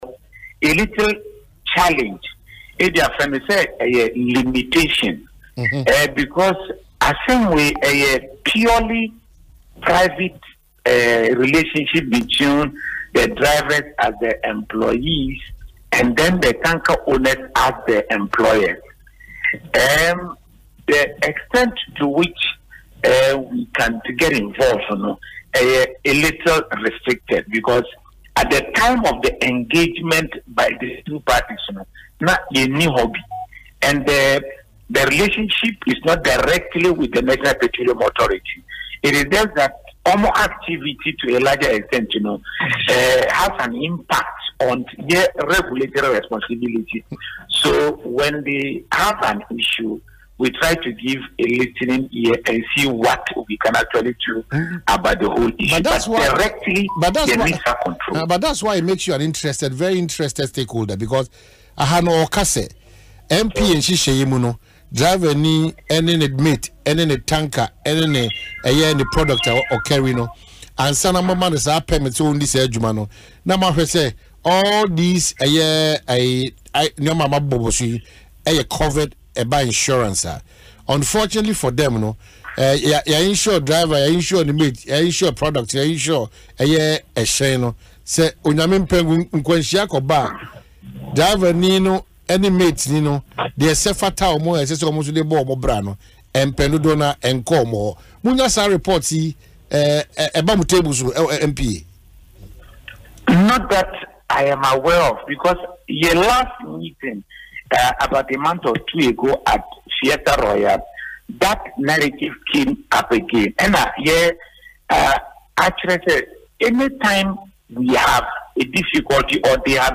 made the appeal on Asempa FM Ekosii Sen programme Wednesday.